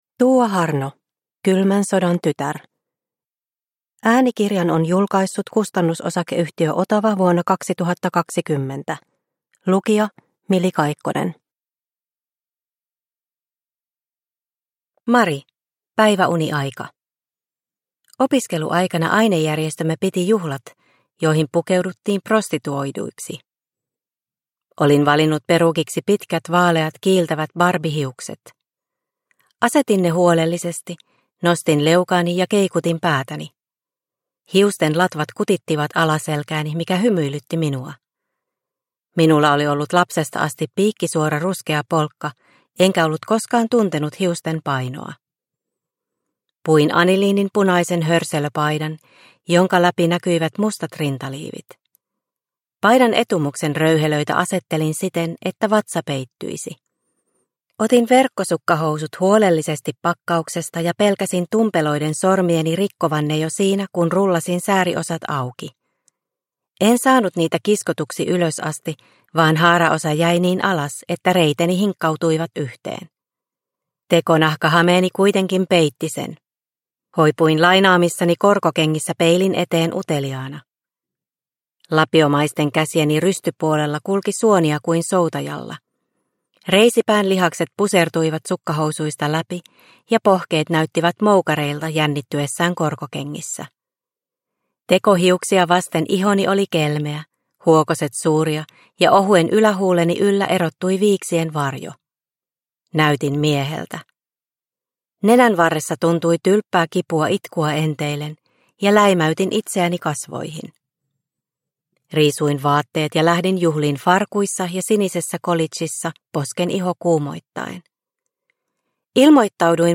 Kylmän sodan tytär – Ljudbok – Laddas ner